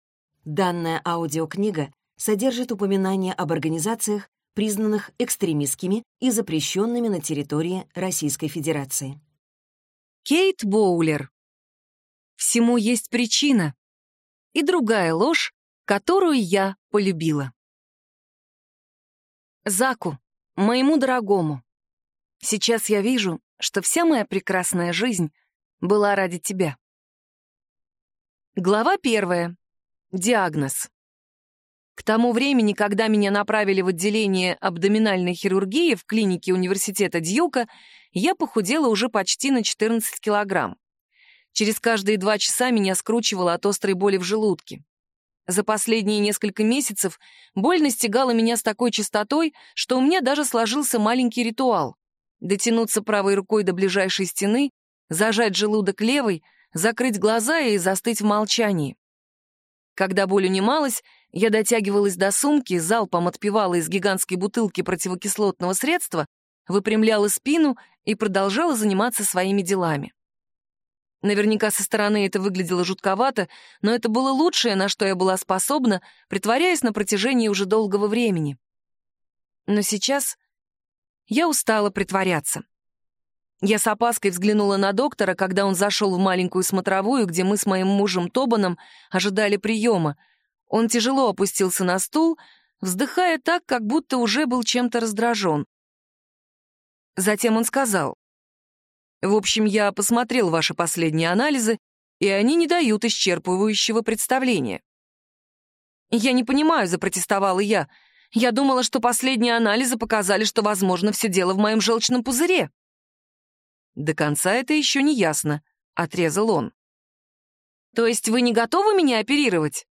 Аудиокнига Всему есть причина… и другая ложь, которую я полюбила | Библиотека аудиокниг